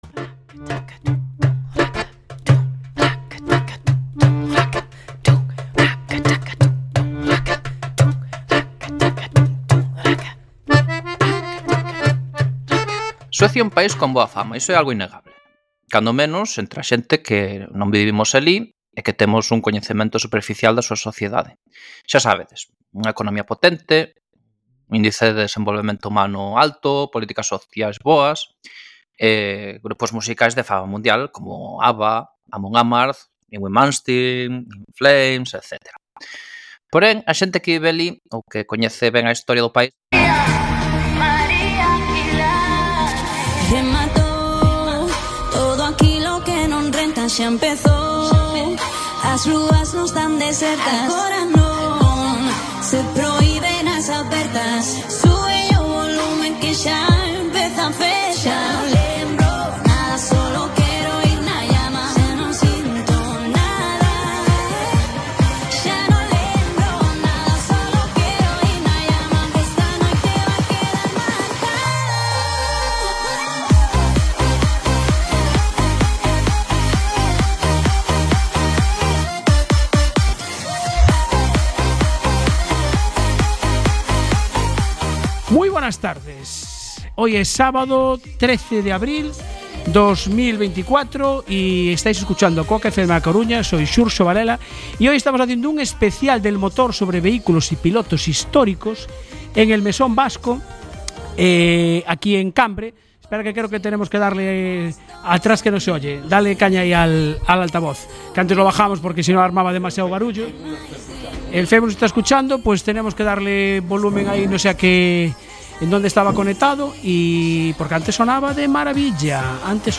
Programa Especial Exteriores motos clásicas.